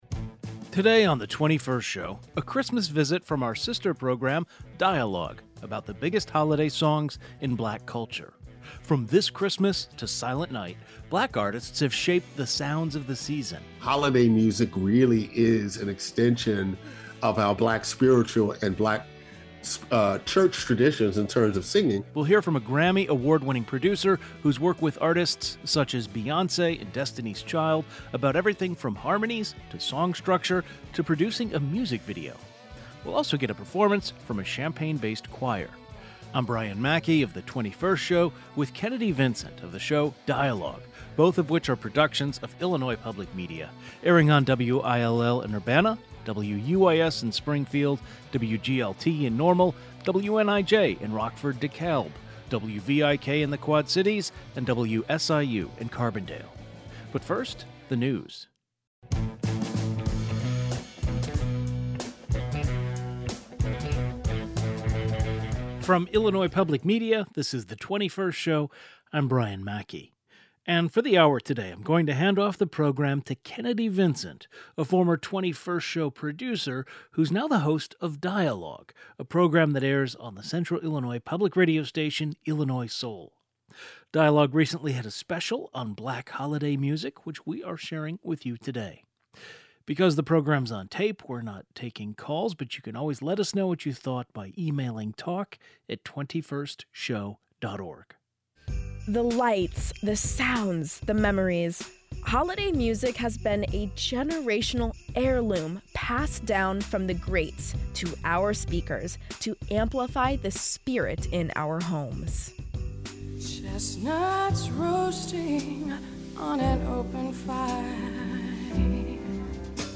Today, we'll hear from our sister program Dialogue taking a look into holiday music in Black culture. A Grammy award-winning music producer who has worked with artists such as Beyonce and Destiny's Childs, a professor specializing in African-American studies, and the director of a Champaign-based choir all join the conversation.